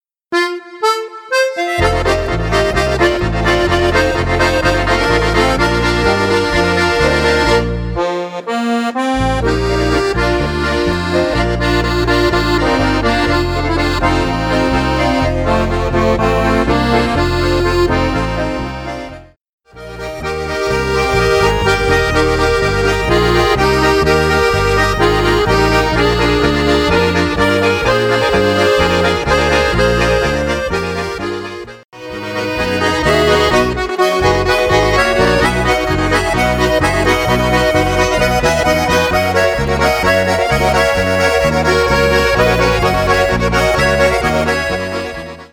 Potpourri